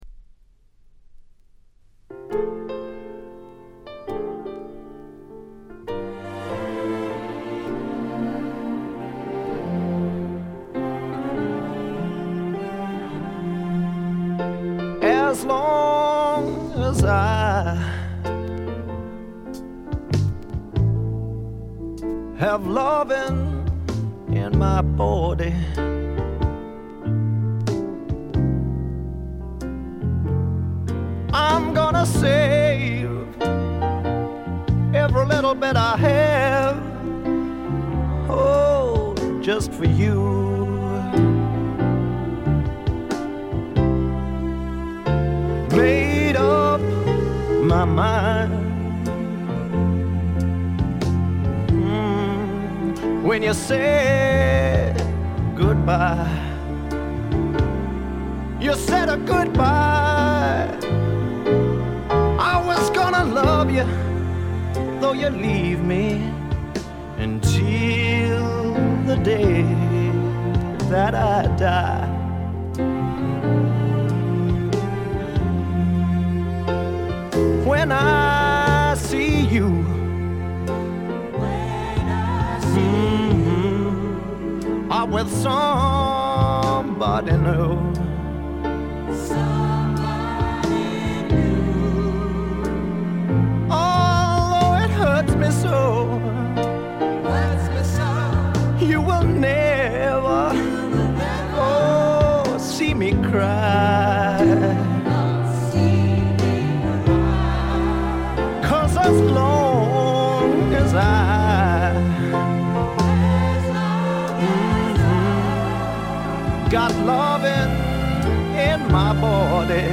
静音部でのバックグラウンドノイズ、チリプチ少し。
試聴曲は現品からの取り込み音源です。
Vocals, Piano